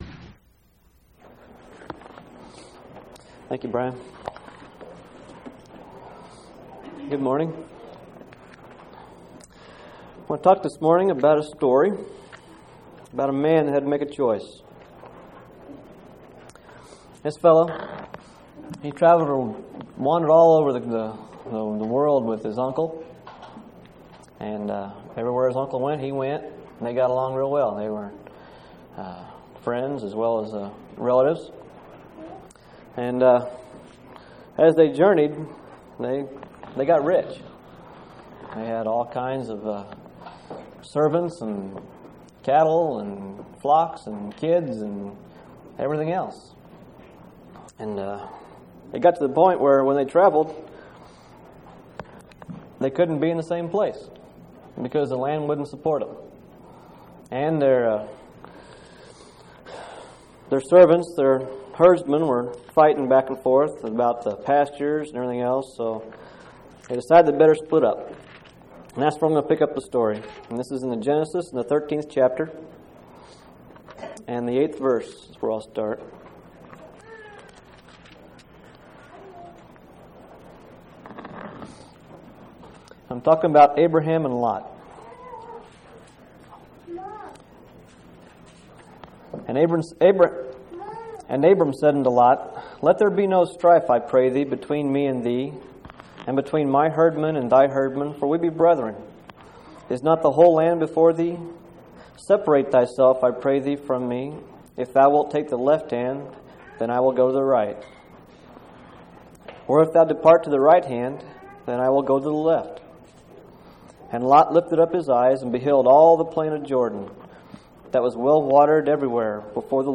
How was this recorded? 7/12/1998 Location: Phoenix Local Event